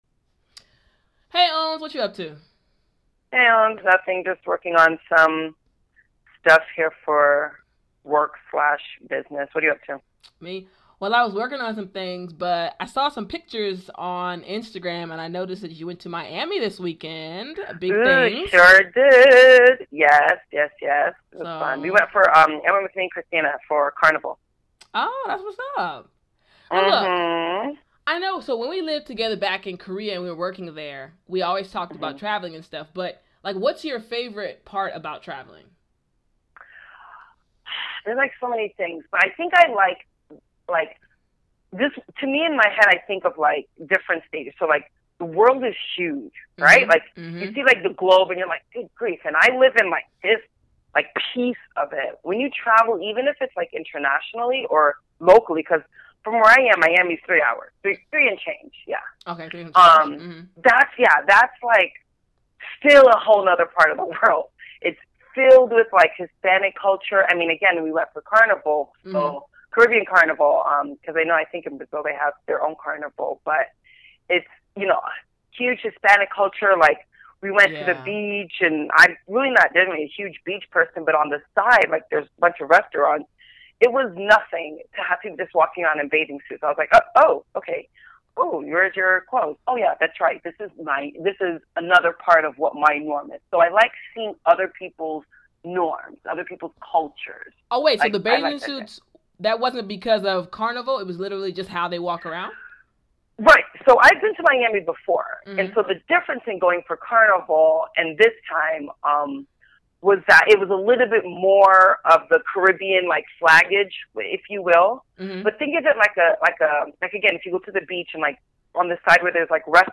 In this lesson you will listen to one REAL English conversation and learn new English expressions.
ORIGINAL CONVERSATION